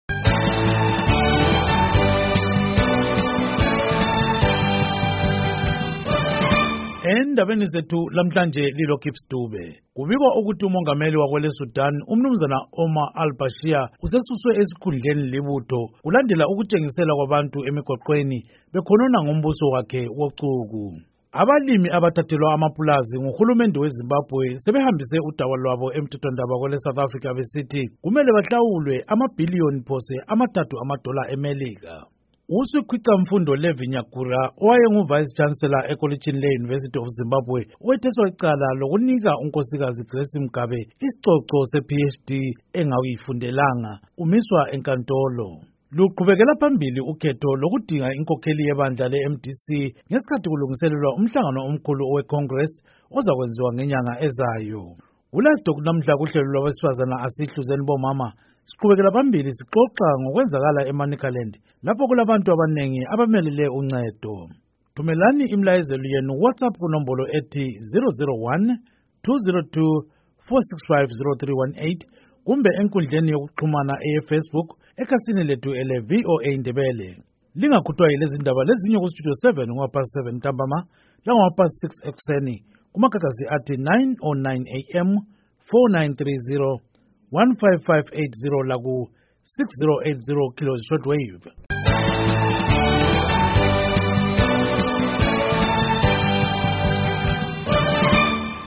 Inhloko zeNdaba, Mabasa 11, 2019